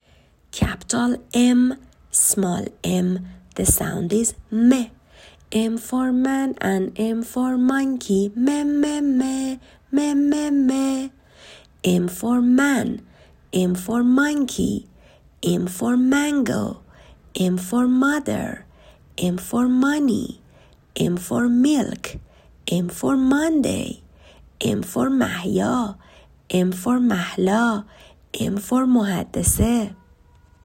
حروفی که بچه ها یاد گرفتند تا اینجا رو در قالب چند ویس ، گذاشتم.
حرف Mm ، صداش و لغاتش